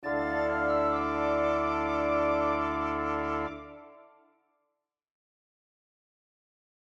Modern Classical Composer/Artist
Plays end of the track